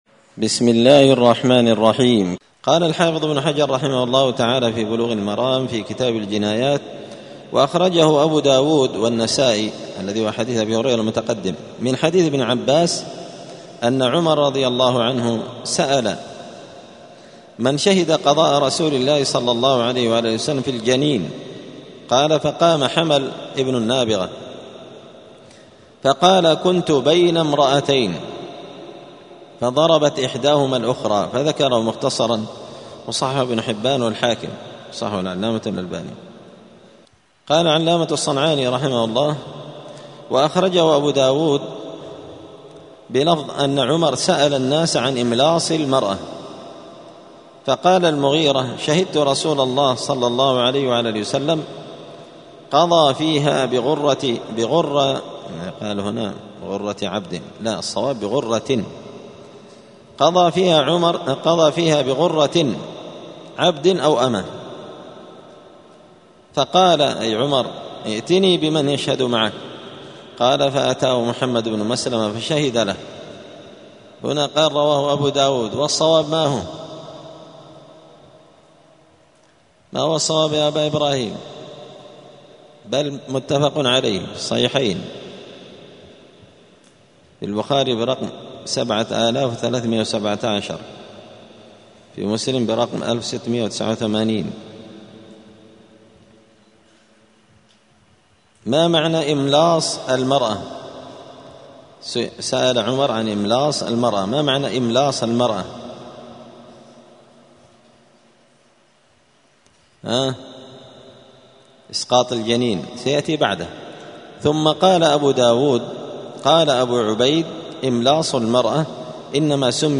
*الدرس الثالث عشر (13) {تابع لباب قلع السن في العمد}*